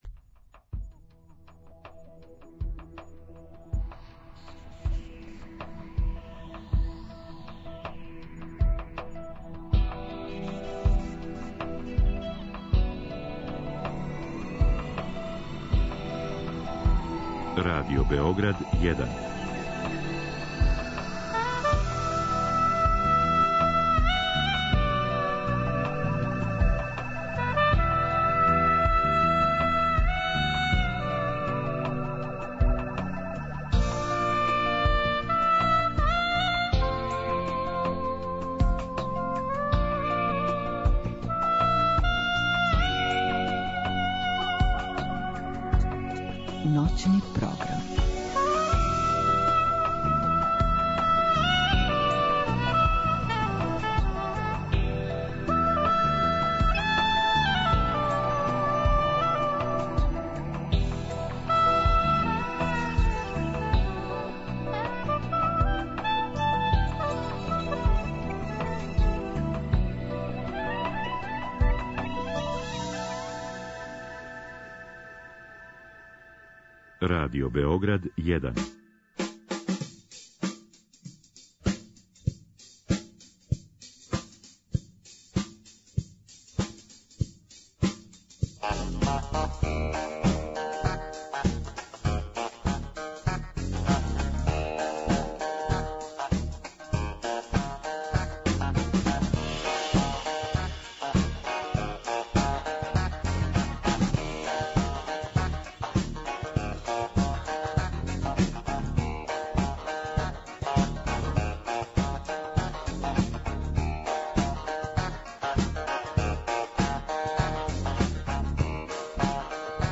Чућете и телефонски разговор са Јурицом Пађен (Парни Ваљак, Азра и Аеродром) и Мирославом Цветковићем - Цвелетом (Бајага и Инструктори).